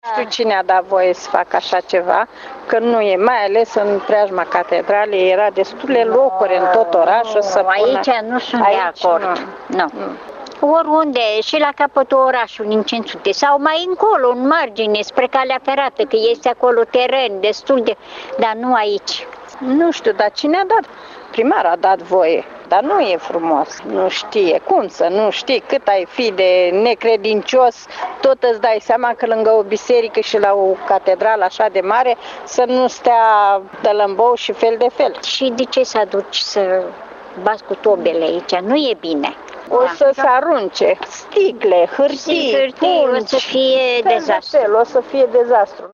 Slobozenii credincioşi sunt nedumeriţi de decizia Primăriei şi îngrijoraţi că se vor distruge alte spaţii verzi ale oraşului: